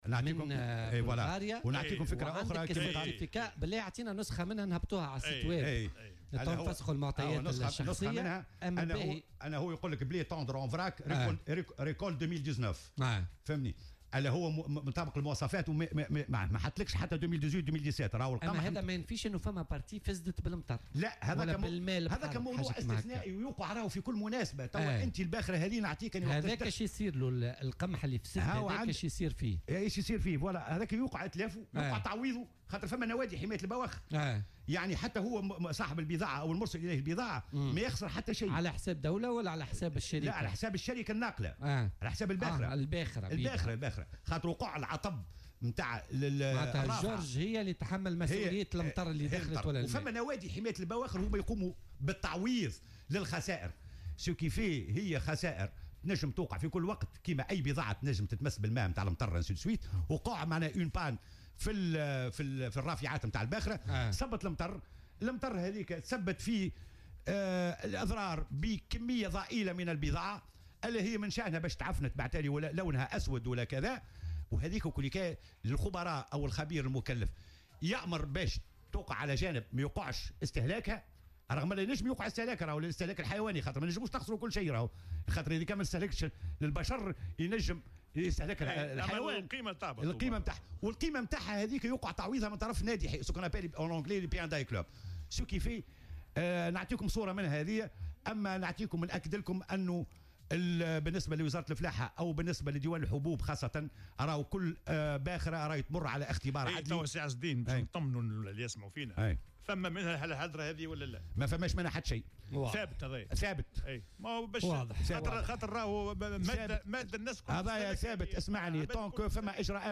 وأضاف في مداخلة له اليوم في برنامج "بوليتيكا" على "الجوهرة أف أم" أن كل عمليات الشحن تتم تحت رقابة خبير عدلي الذي يشرف على كامل مراحل عملية التوريد، وفق تعبيره.